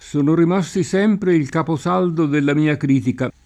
caposaldo [kapoS#ldo] s. m.; pl. capisaldi (meno com. caposaldi) — antiq. capo saldo [id.]: sono rimasti sempre il capo saldo della mia critica [